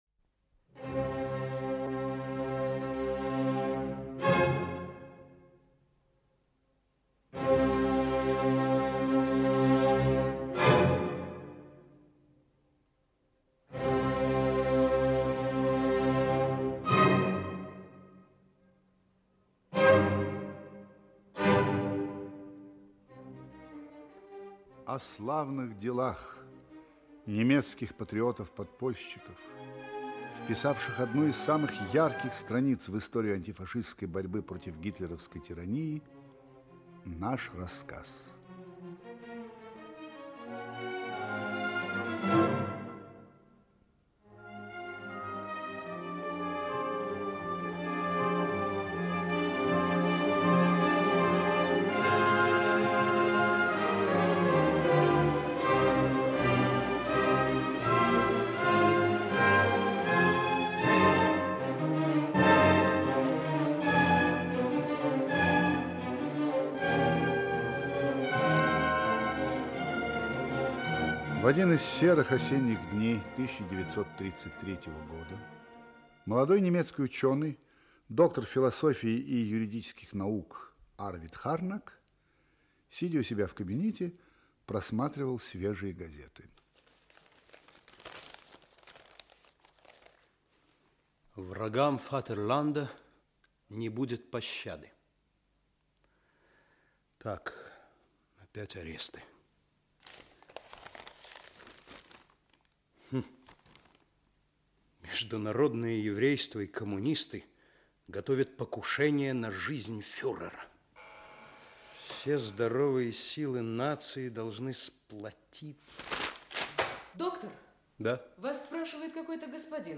Аудиокнига "Альта" - пароль срочного вызова | Библиотека аудиокниг
Aудиокнига "Альта" - пароль срочного вызова Автор Сергей Демкин Читает аудиокнигу Владимир Высоцкий.